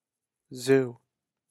Voiced alveolar fricative - Wikipedia
• Its phonation is voiced, which means the vocal cords vibrate during the articulation.
English zoo
[zuː] 'zoo' Absent from some Scottish and Asian dialects. See English phonology